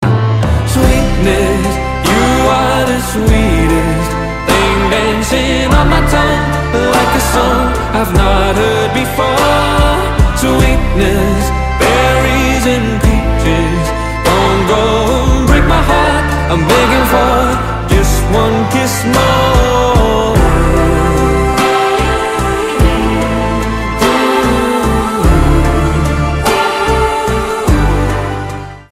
pop rock
красивый мужской голос